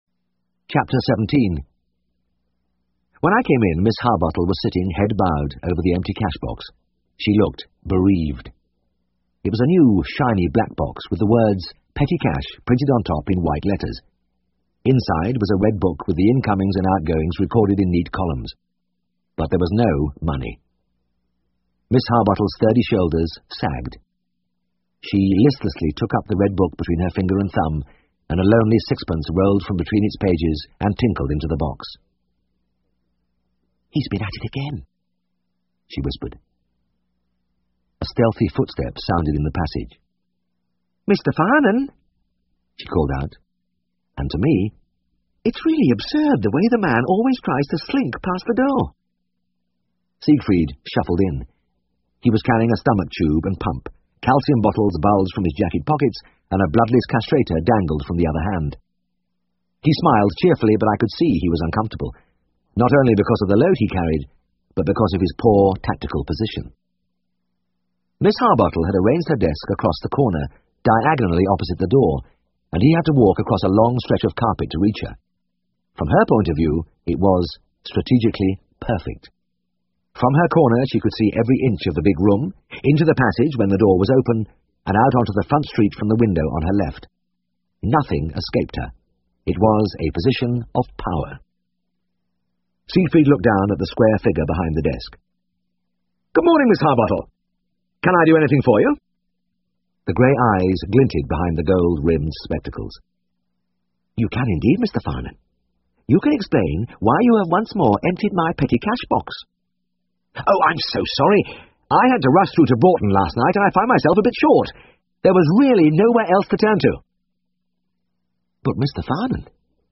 英文广播剧在线听 All Creatures Great and Small 37 听力文件下载—在线英语听力室